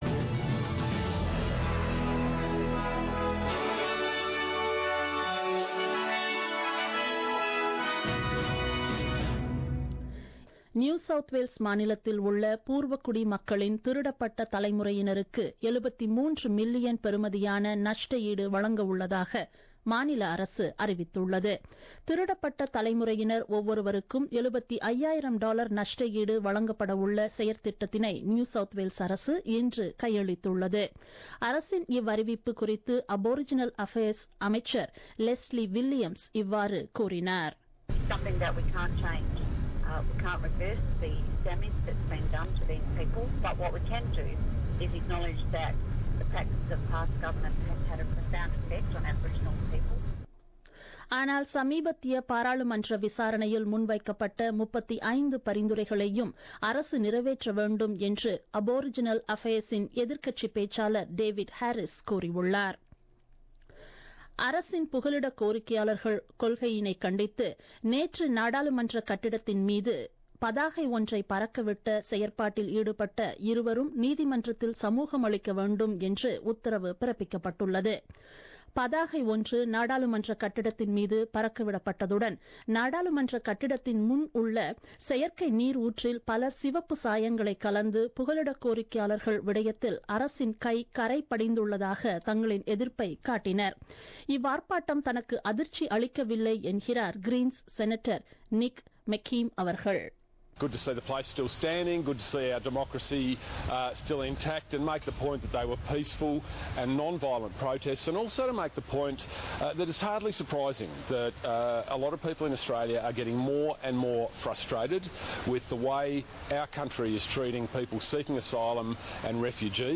The news bulletin broadcasted on 02 Dec 2016 at 8pm.